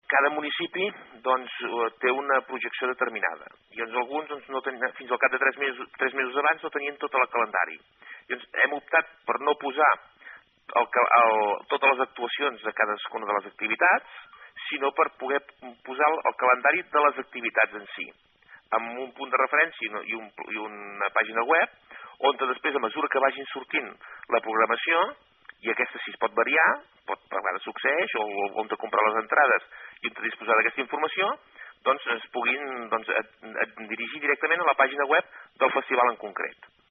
En declaracions a aquesta emissora, el president del Consorci de Promoció Turística Costa del Maresme, Joaquim Arnó, ha explicat que la Guia té per objectiu esdevenir una referència per aquell visitant que vulgui consultar quina és l’oferta cultural i musical de la comarca en un sol lloc.